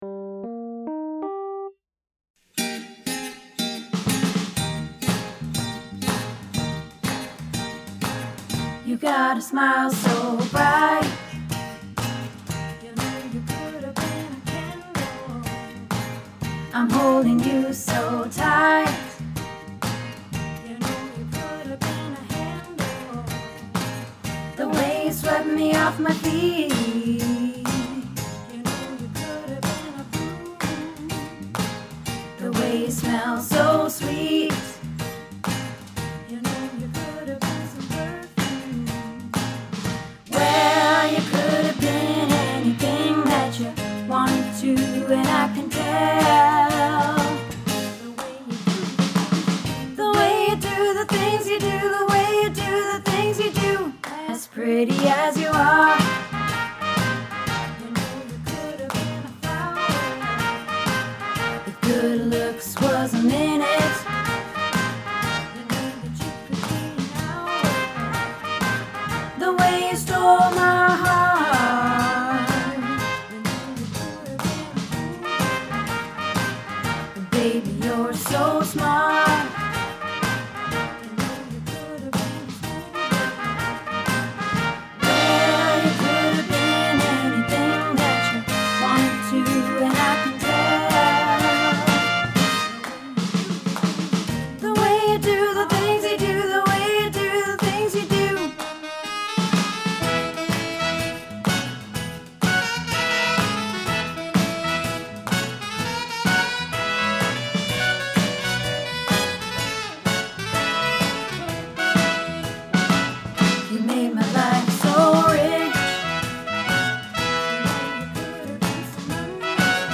The Way You Do the Things You Do – Tenor | Happy Harmony Choir